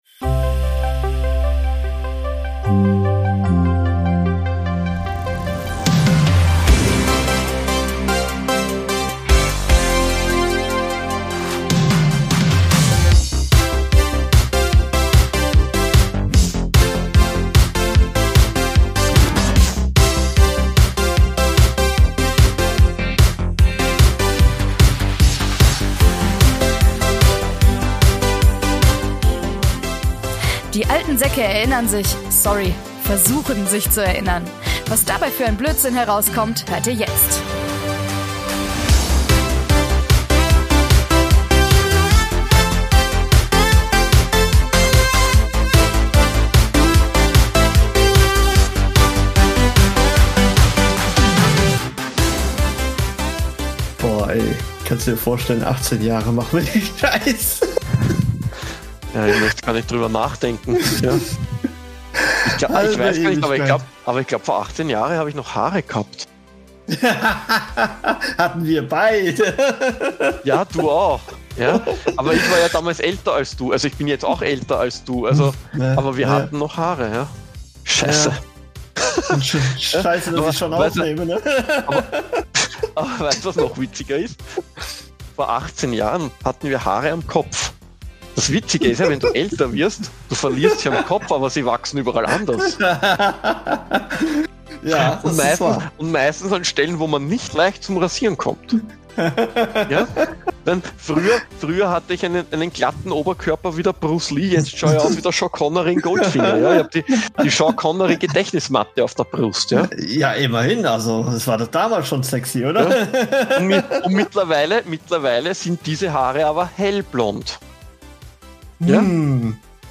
Beschreibung vor 7 Monaten Wie versprochen, kommt hier unser zweiter Teil des GameFeature Jubiläums-Podcasts!